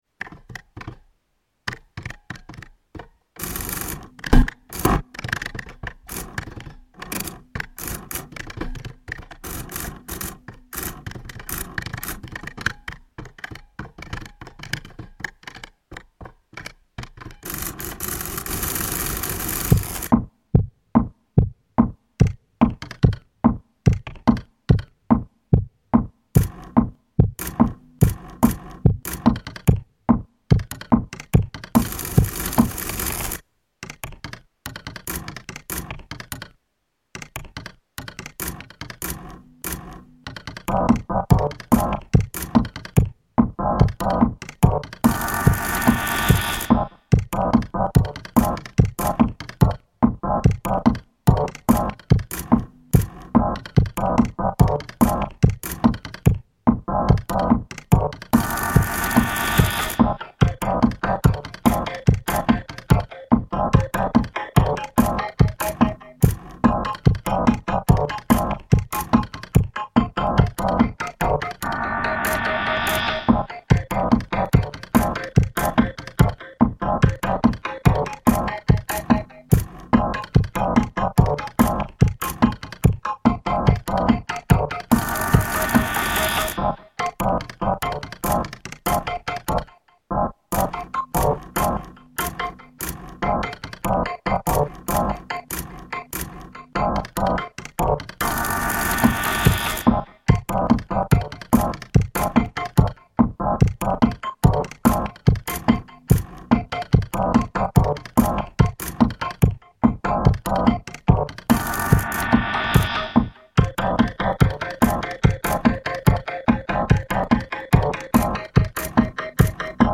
The result is a rather "machiney" and upbeat, repetitive piece.